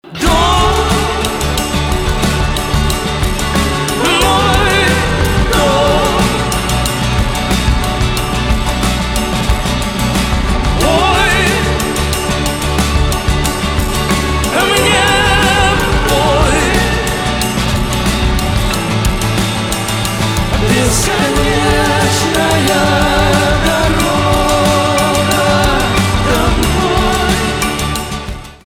поп
чувственные
барабаны , гитара